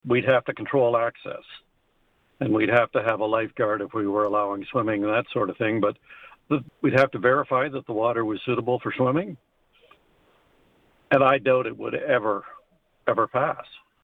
However, Stirling-Rawdon Mayor Bob Mullin says opening the quarry up for recreational use would be very difficult to justify and would be beyond the community’s ability to pay.